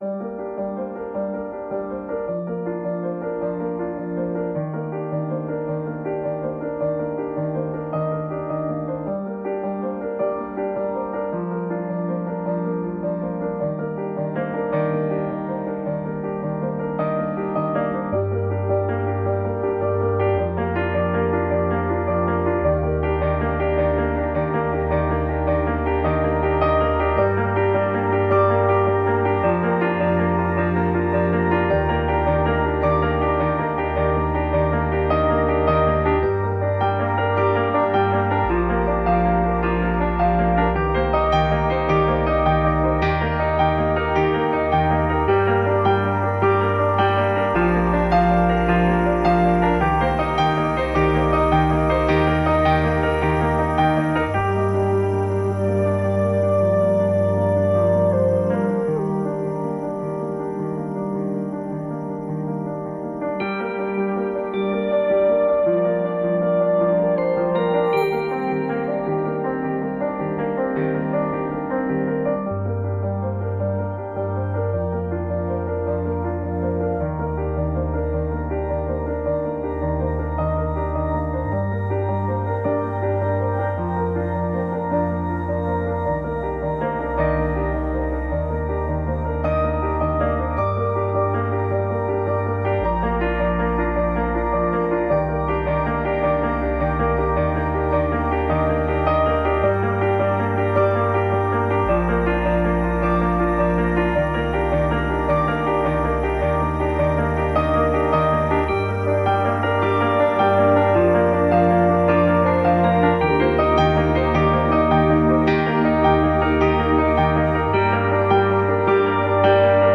Piano Spring Performance
piano.mp3